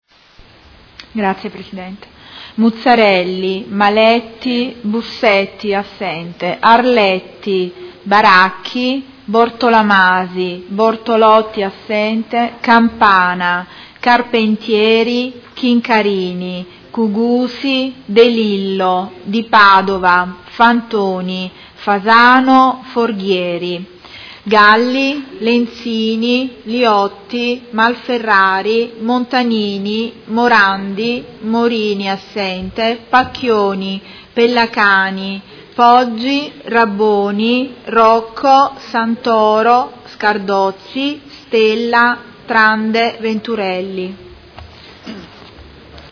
Seduta del 14/12/2017 Appello.
Segretaria